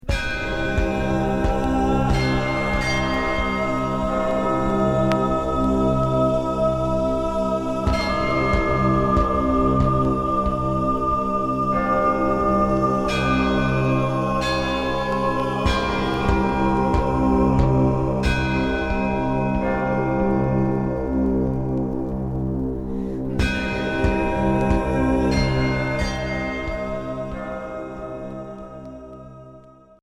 Cold gothique mystique